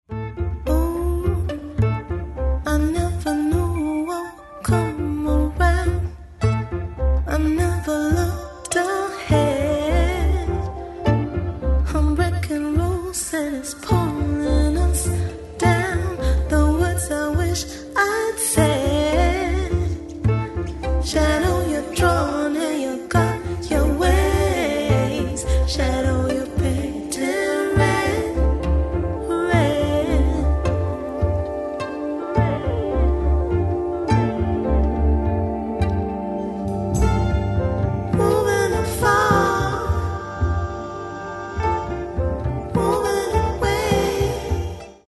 Genre : Hip-hop/Rap